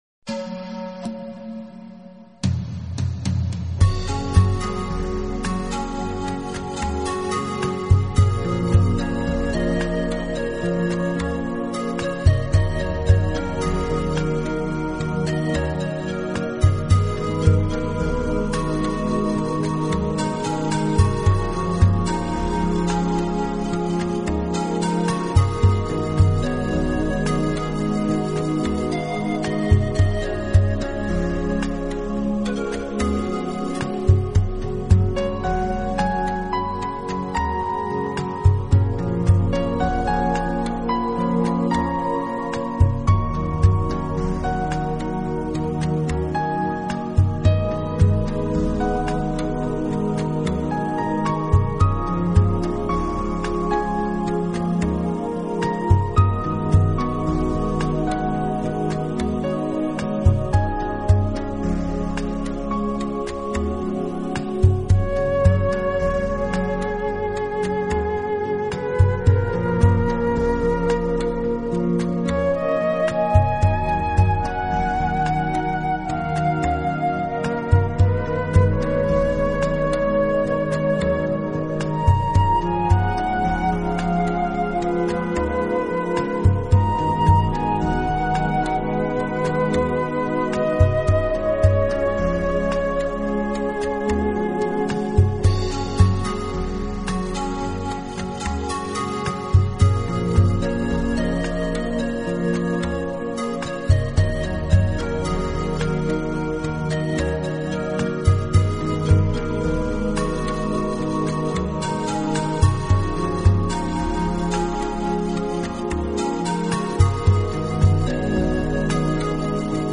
【新世纪纯音乐】
旋律轻柔舒缓，节奏平和，结合了“精、气、神”三者合一的